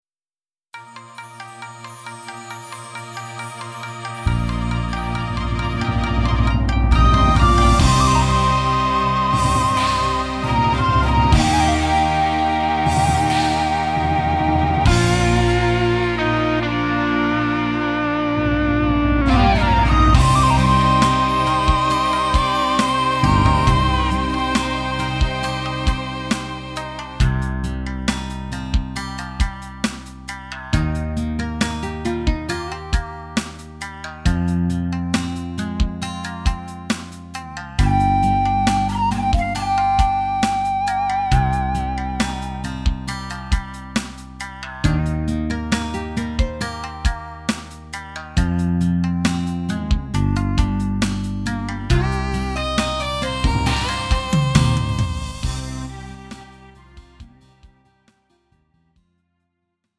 음정 원키 4:57
장르 가요 구분 Lite MR